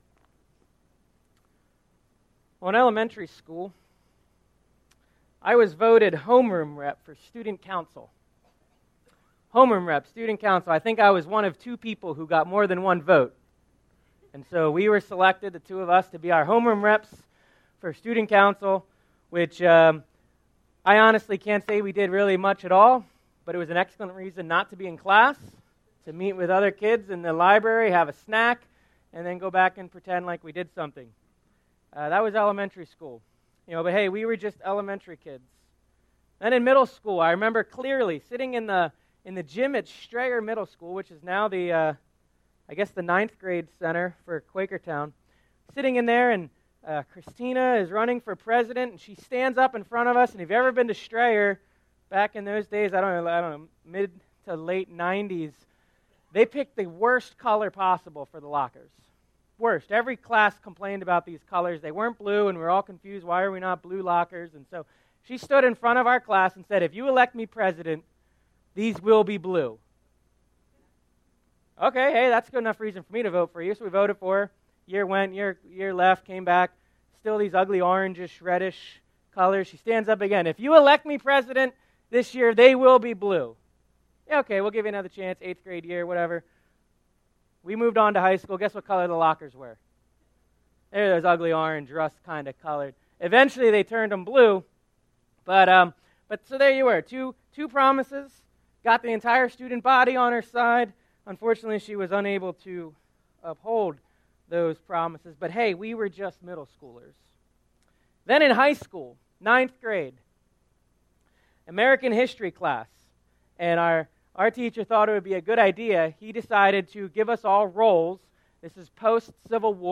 Finland Mennonite Church in Pennsburg, Pennsylvania, a Christ-centered Anabaptist Congregation.
Sermon Series